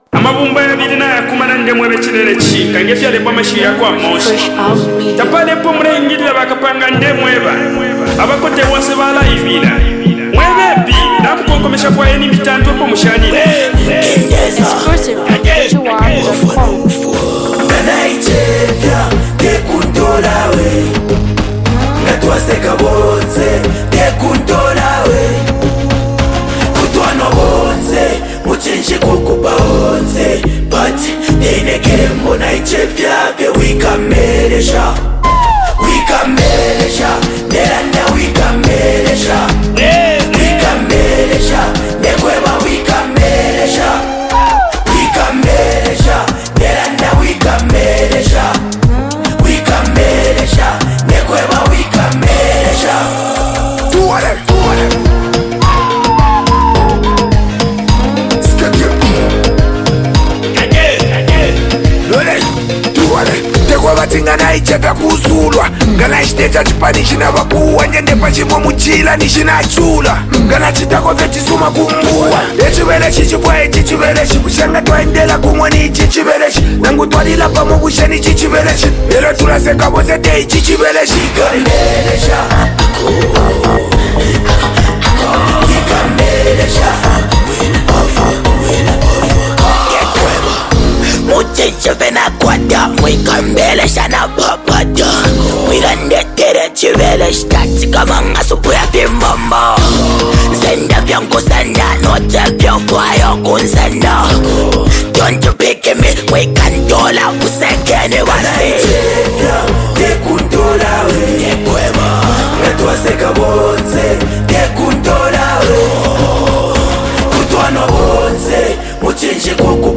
an explosive fusion of beats, lyrics, and pure energy
From its catchy hooks to its irresistible rhythm
this track is guaranteed to keep you dancing all night long.